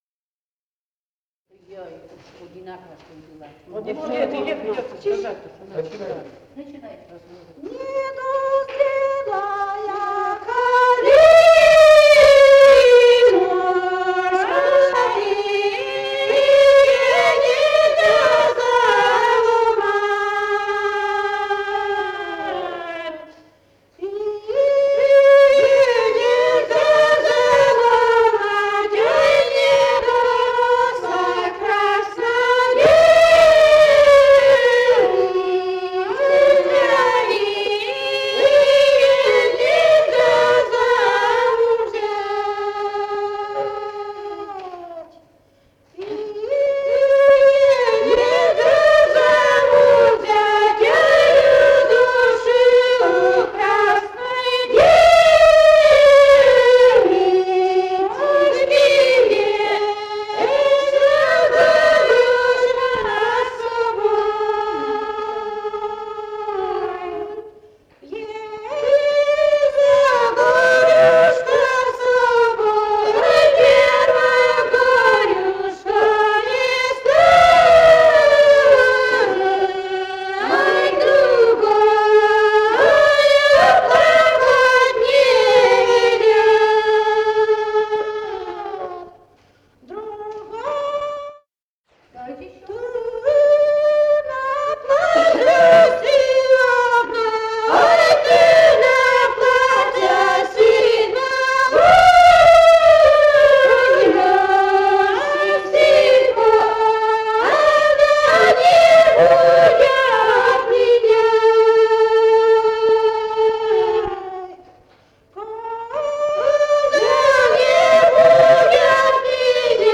«Недозрелая калинушка» (лирическая).